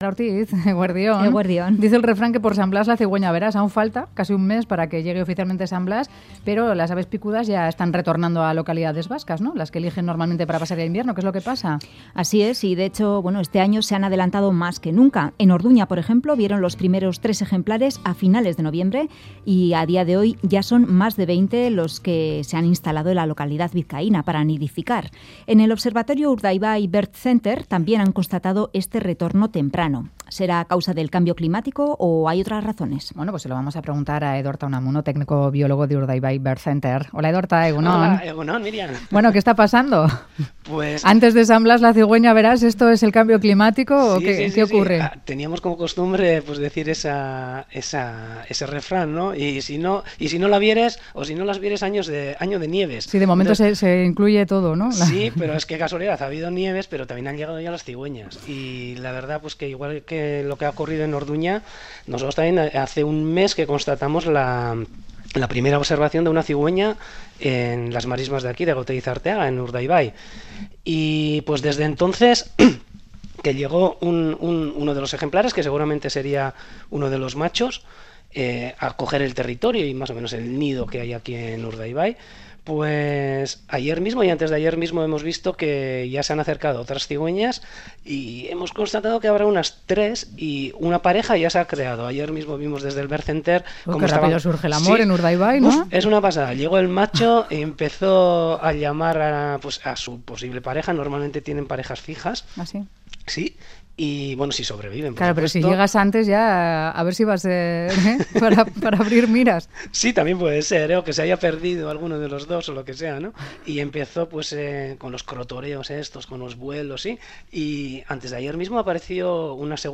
Audio: Cigüeñas en Bizkaia antes de tiempo. Entrevista